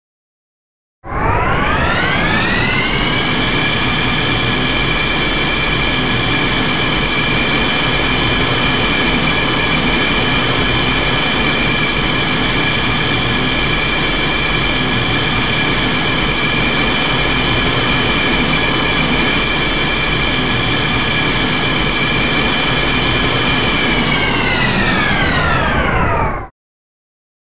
soundmodul Turbinensound
turbine_kurz.MP3